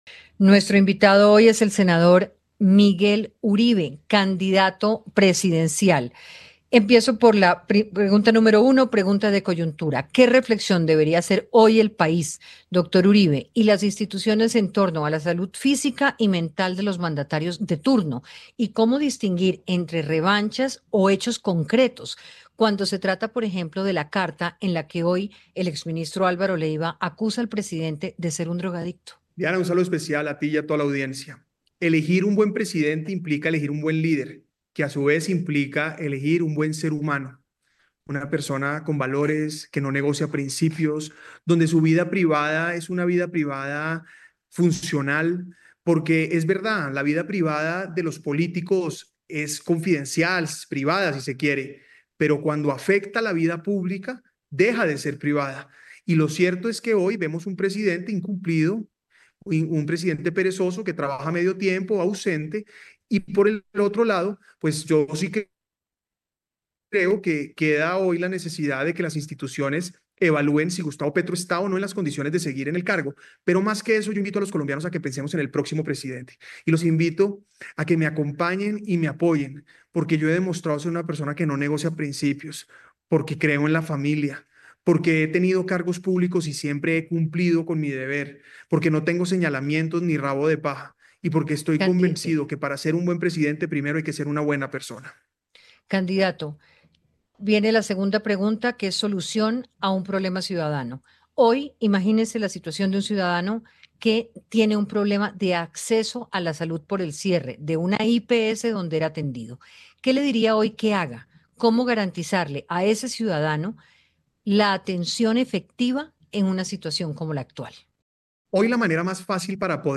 En diálogo con Hora20 de Caracol Radio, el precandidato Miguel Uribe habló sobre cómo diferenciar entre revanchas y hechos concretos a propósito de la carta de Álvaro Leyva al presidente Petro.